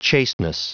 Prononciation du mot chasteness en anglais (fichier audio)